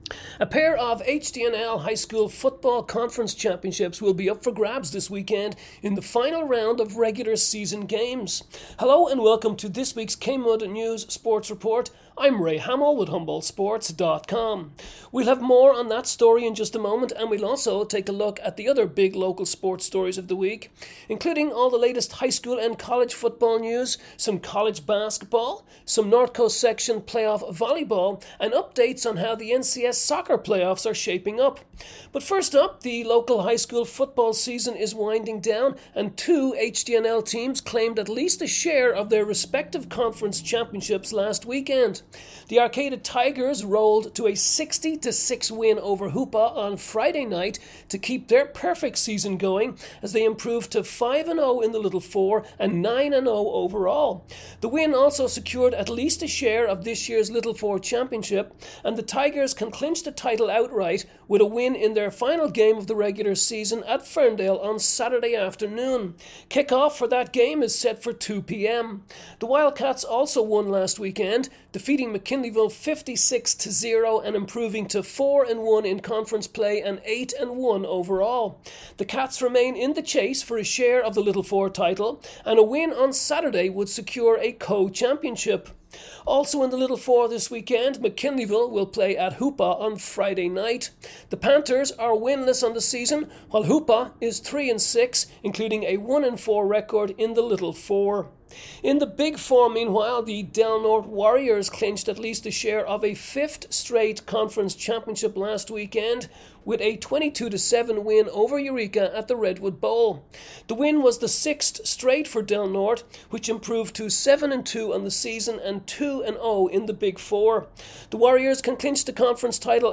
NOV 7 Kmud Sports Report